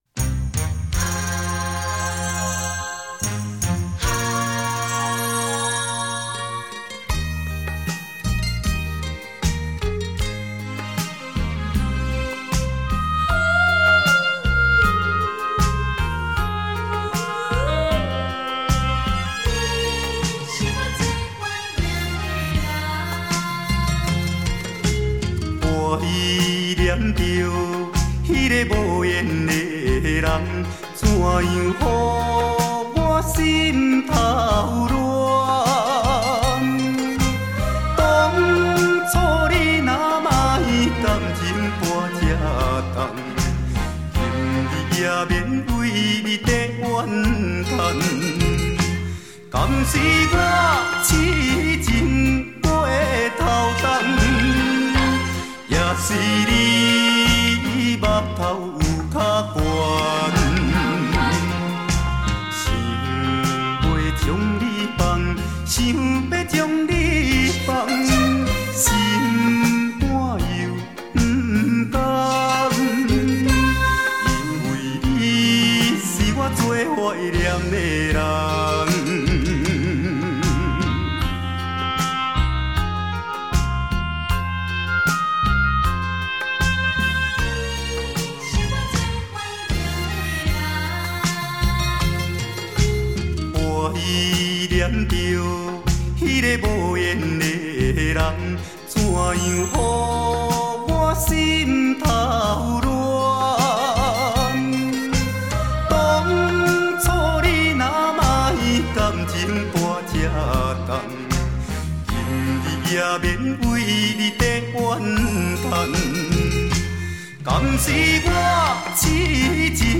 台湾台语男歌手